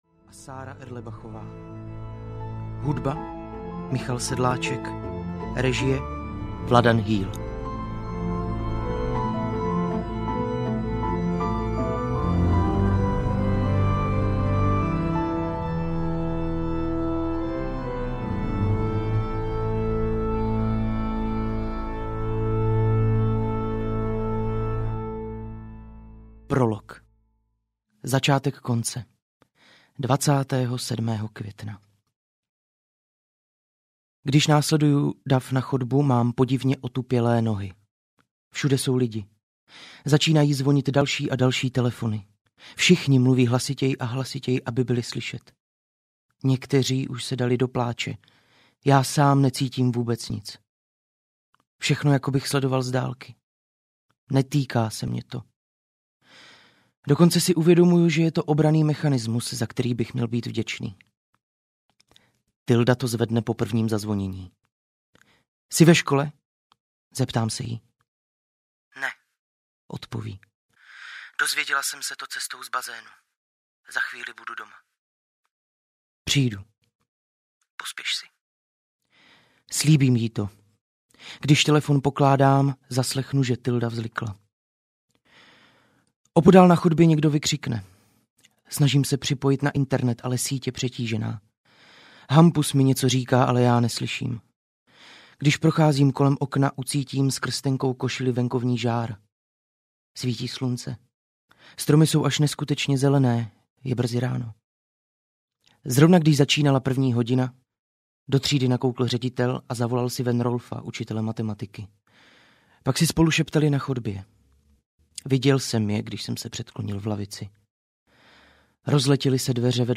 Konec audiokniha
Ukázka z knihy